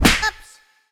hero_death.ogg